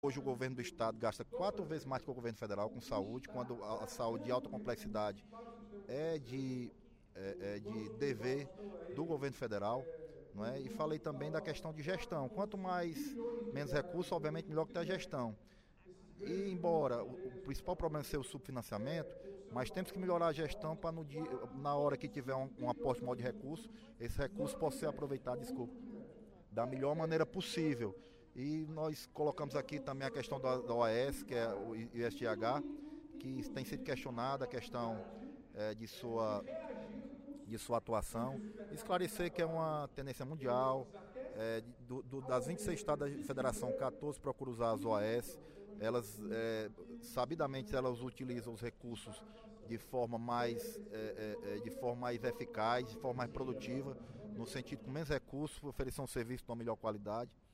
O vice-líder do Governo na Assembleia Legislativa, deputado Leonardo Pinheiro (PSD), defendeu, no segundo expediente da sessão plenária desta quarta-feira (12/08), a eficiência do Instituto de Saúde e Gestão Hospitalar (ISGH) na administração dos equipamentos de saúde do Ceará.